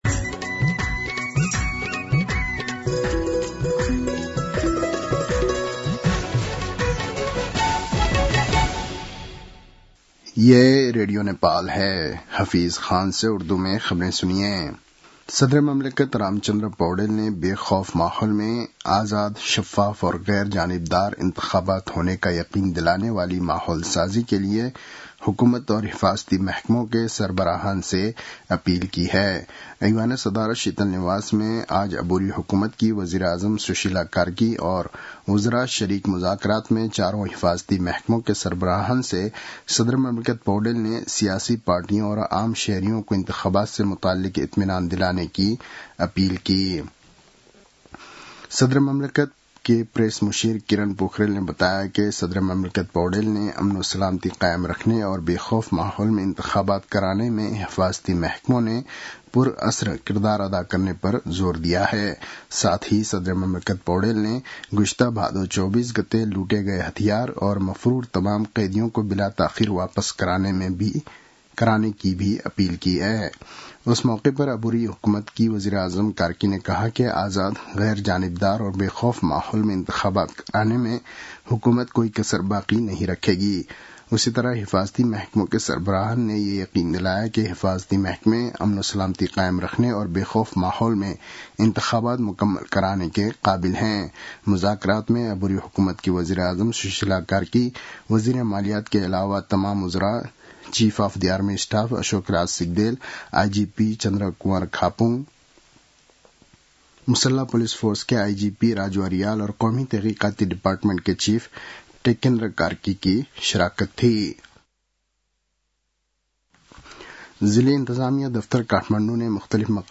उर्दु भाषामा समाचार : १ कार्तिक , २०८२